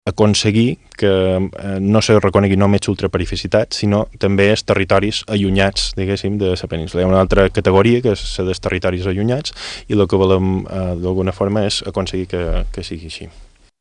Són declaracions a la secció d’economia de l’Informatiu Vespre d’IB3 Ràdio.